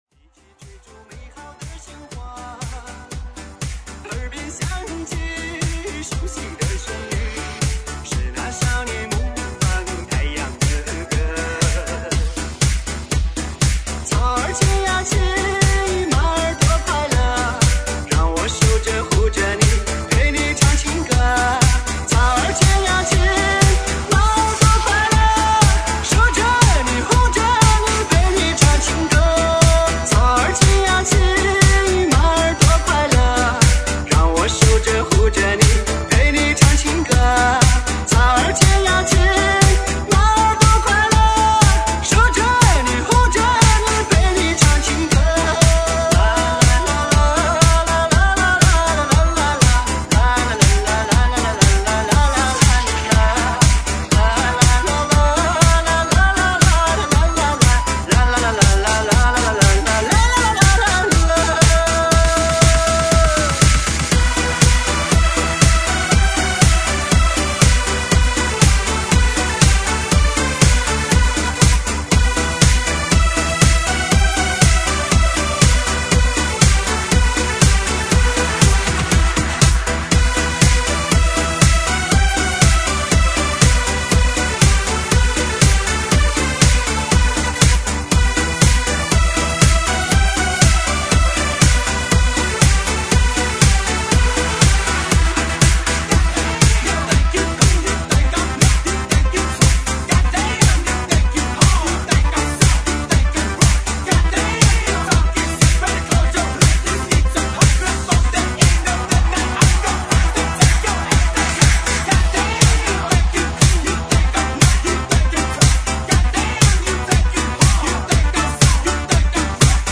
截断试听为低音质